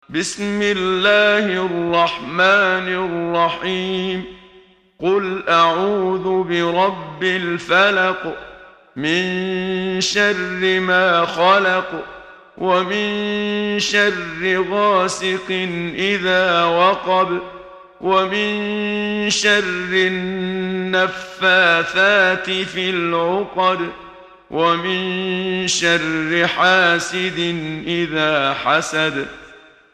محمد صديق المنشاوي – ترتيل – الصفحة 9 – دعاة خير